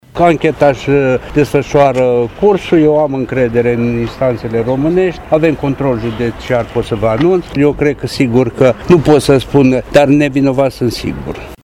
La ieșirea din sala Tribunalului Brașov, primarul localității Ghimbav, Toma Dorel s-a declarat nevinovat…